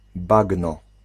Ääntäminen
US : IPA : /mɑɹʃ/ UK : IPA : /mɑː(ɹ)ʃ/